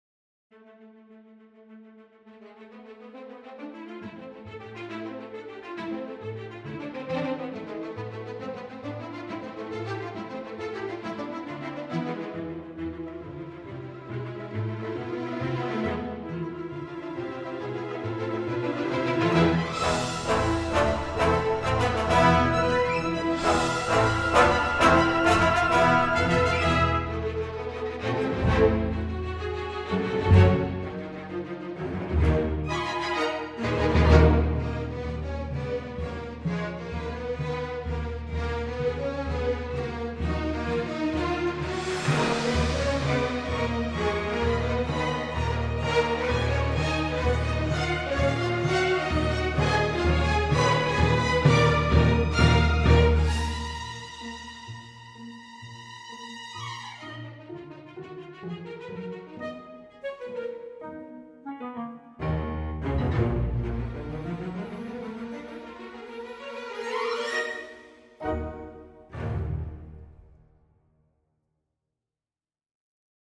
以下试听除弦乐外的其他乐器和打击乐均来自柏林系列，本站均可下载